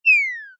ENC_Lose_head_down.ogg